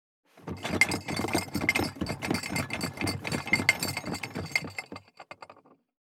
178,地震,引っ越し,荷物運び,段ボール箱の中身,部署移動,ザザッ,ドタドタ,バリバリ,カチャン,ギシギシ,ゴン,ドカン,ズルズル,タン,パタン,ドシン,
効果音荷物運び